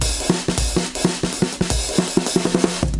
Drumloops " dnb drumloop填充2bars 160bpm
Tag: 低音 沟槽 节奏 碎拍 drumloop drumloop 断线 N 160bpm 节拍 DNB drumgroove